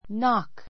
nɑ́k ナ ク （ ⦣ kn- で始まる語は k を発音しない